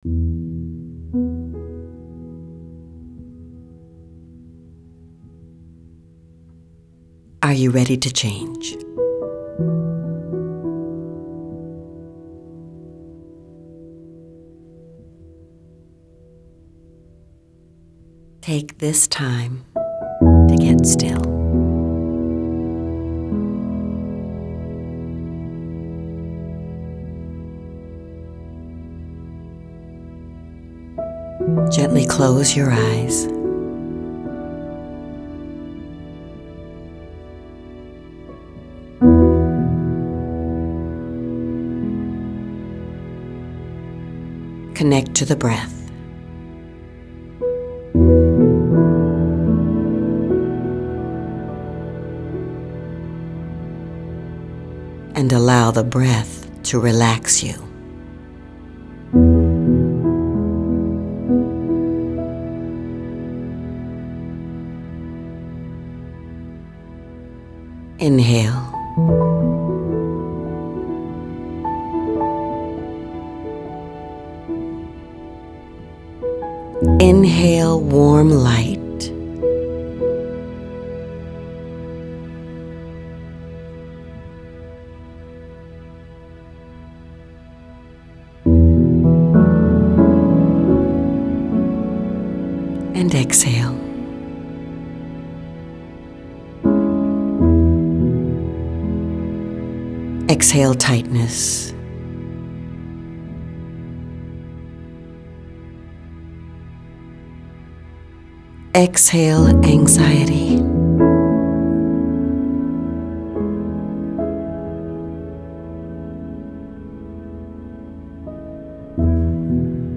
Are You Ready to Change Meditation
02-are-you-ready-to-change-instrumental.m4a